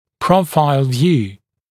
[‘prəufaɪl vjuː][‘проуфайл вйу:]вид в профиль, вид сбоку